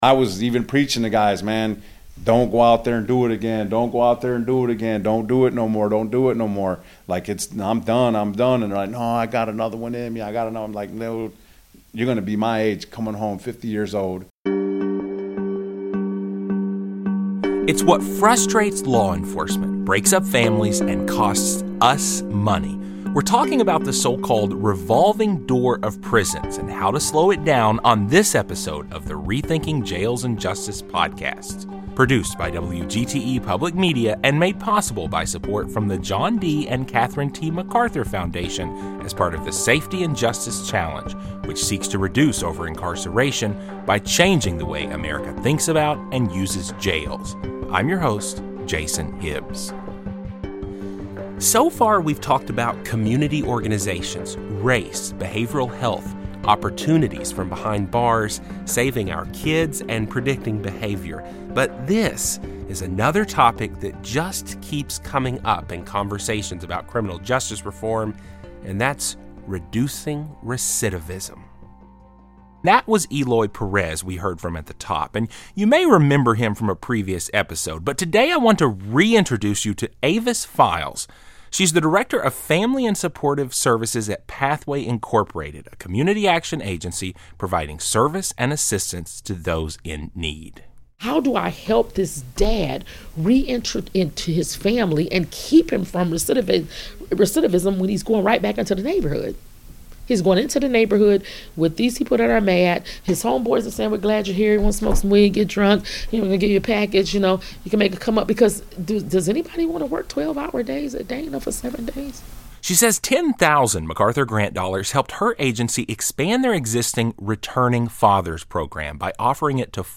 This exploration of the so-called “revolving door of prison” features a former inmate, who passionately warns younger men against falling into the same traps, along with a local director who says she’s dedicated to helping returning fathers reintegrate into their families and communities.